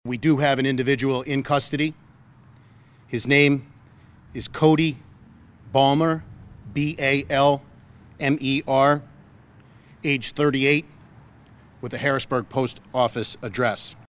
PENNSYLVANIA GOVERNOR JOSH SHAPIRO AND HIS FAMILY WERE VICTIM TO WHAT STATE POLICE ARE CALLING AN ARSON AFTER THEY CELEBRATED PASSOVER AT THE GOVERNOR’S MANSION. PENNSYLVANIA STATE POLICE COMMISSIONER COLONEL CHRISTOPHER PARIS SAYS POLICE HAVE A SUSPECT….